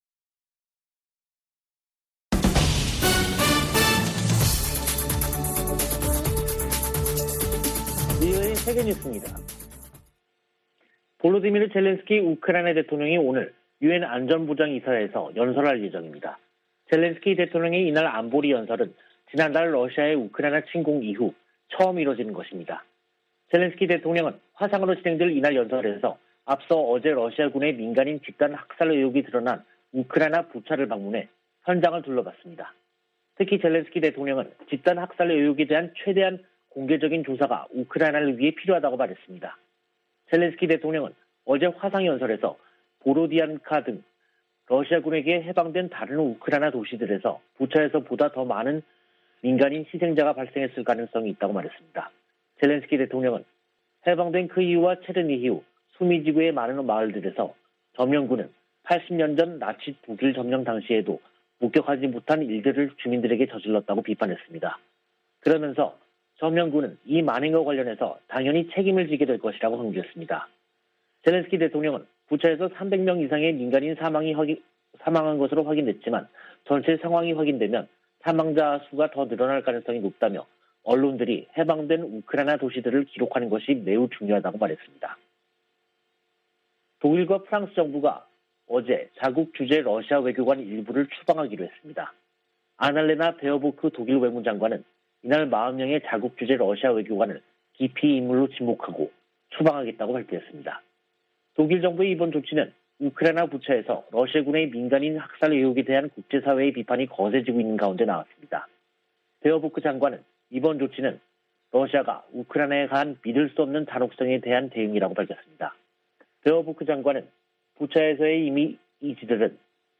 VOA 한국어 간판 뉴스 프로그램 '뉴스 투데이', 2022년 4월 5일 3부 방송입니다. 미 국무부는 한국의 윤석열 차기 정부가 전략동맹 강화를 언급한 것과 관련해 “한국은 중요한 조약 동맹”이라고 말했습니다. 미·한 북핵대표가 북한의 최근 ICBM 발사에 대한 새 유엔 안보리 결의안 추진 의사를 밝혔습니다.